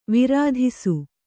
♪ virāḍhisu